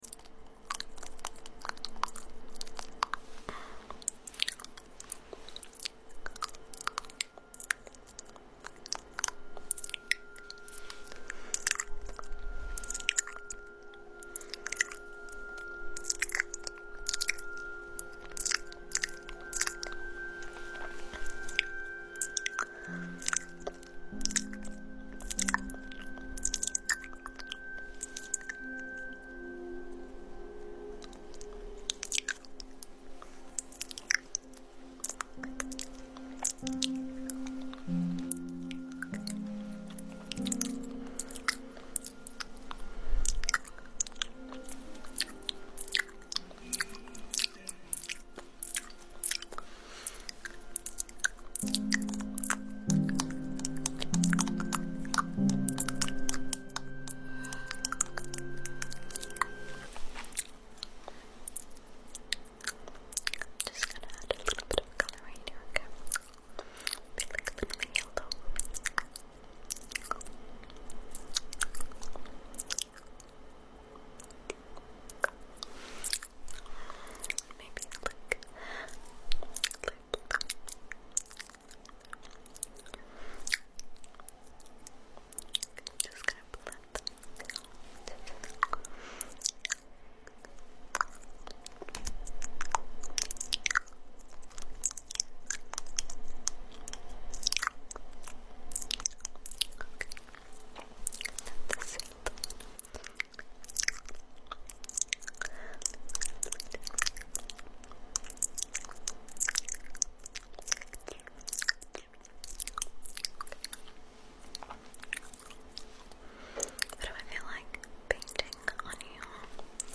Spit painting